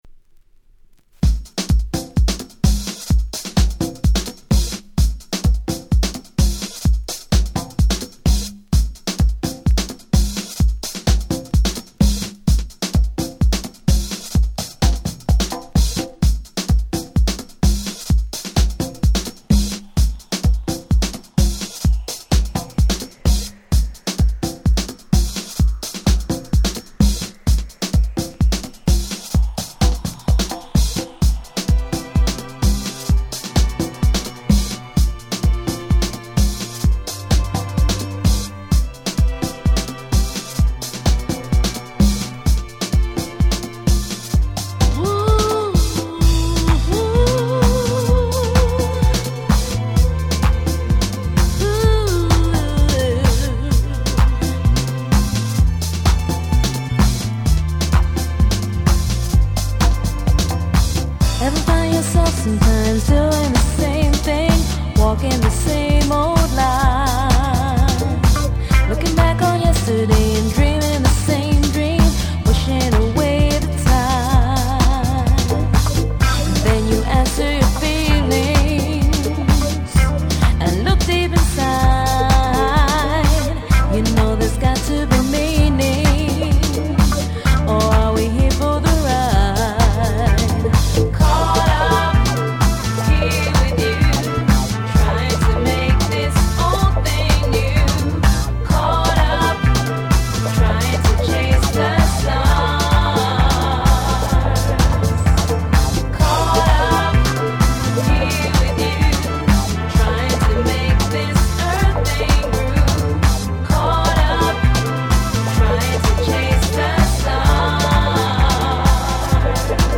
98' Super Hit Vocal House !!
ボーカルハウス